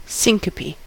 syncope: Wikimedia Commons US English Pronunciations
En-us-syncope.WAV